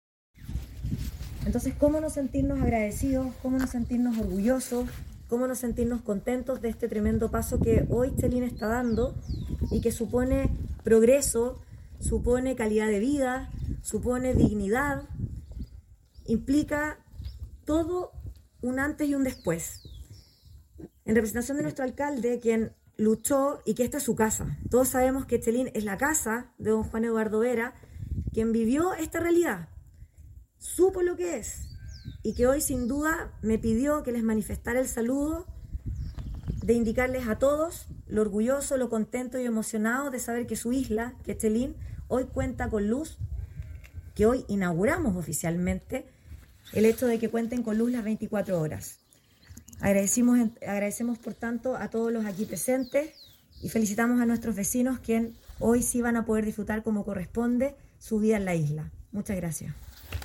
Administradora.mp3